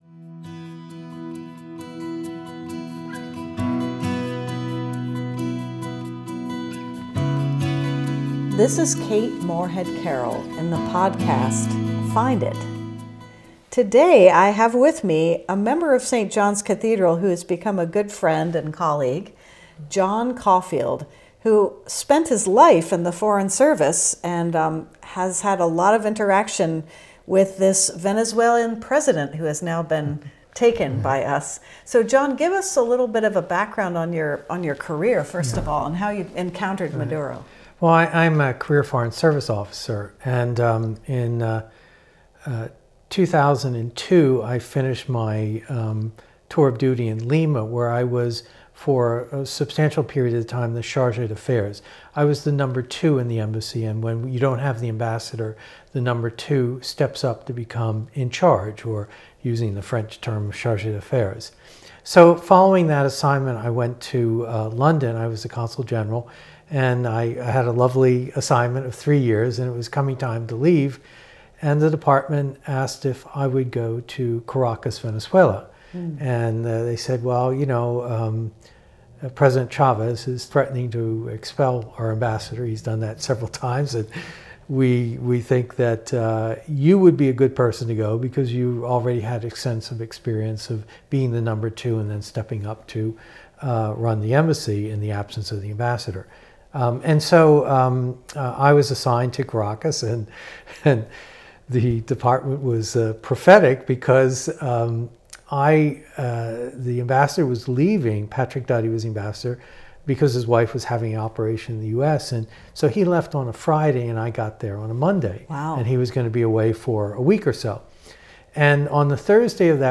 sits down with John Caulfield, retired Senior Foreign Service Officer and former Acting Ambassador to Venezuela (2008–2011). Drawing on his firsthand experience in Caracas during Hugo Chávez’s presidency, Caulfield unpacks how Nicolás Maduro rose from bus driver and labor activist to the presidency of Venezuela—and how power, corruption, and foreign influence shaped that journey.